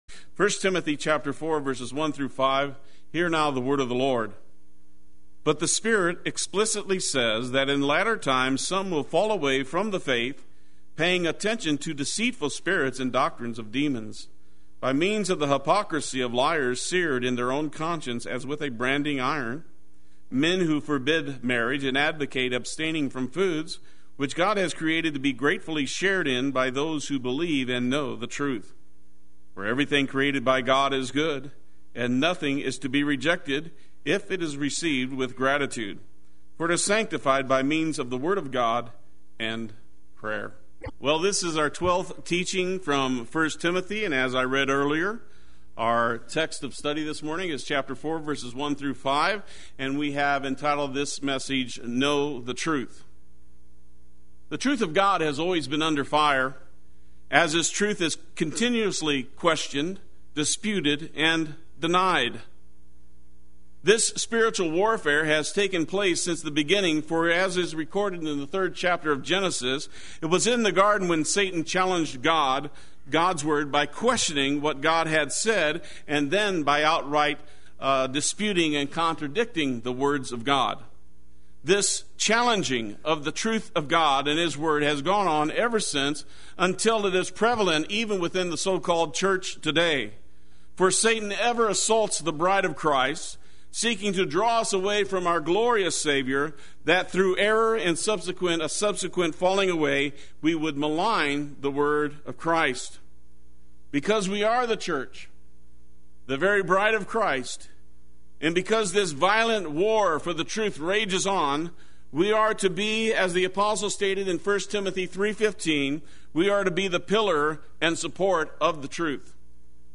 Play Sermon Get HCF Teaching Automatically.
Know the Truth Sunday Worship